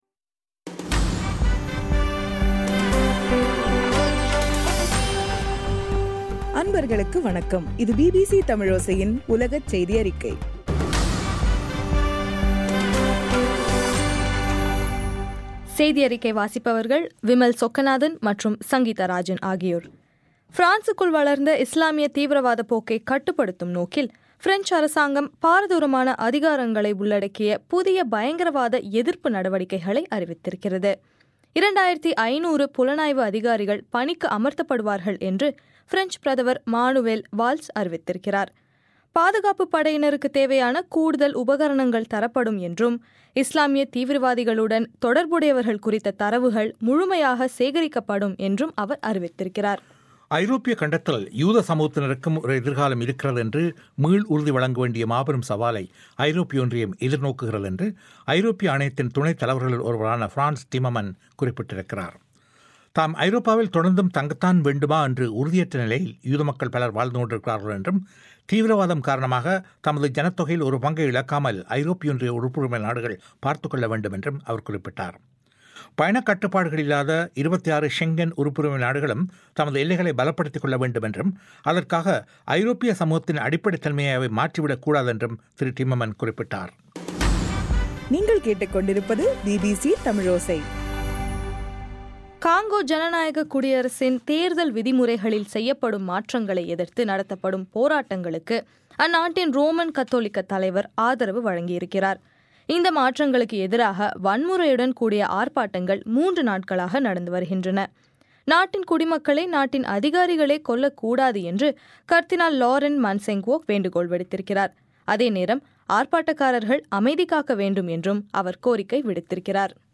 ஜனவரி 21 2015 பிபிசி தமிழோசையின் உலகச் செய்திகள்